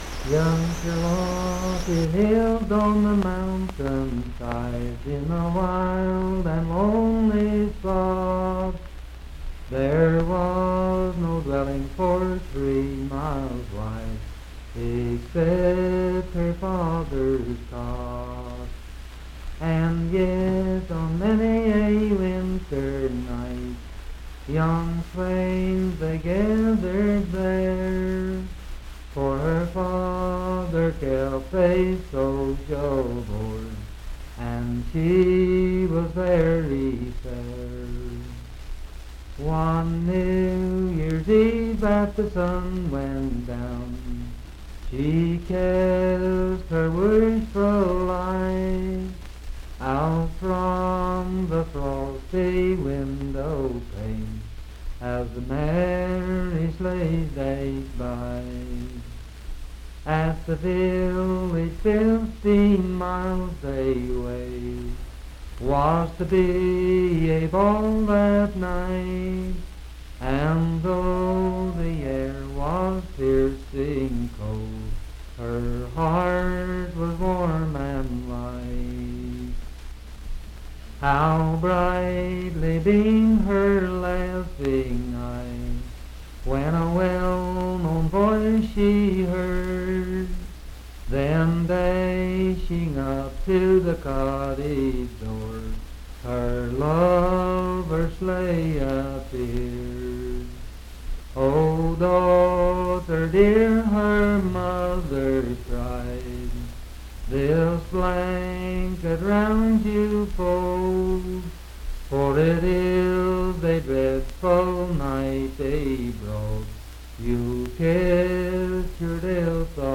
Unaccompanied vocal music
Voice (sung)
Marlinton (W. Va.), Pocahontas County (W. Va.)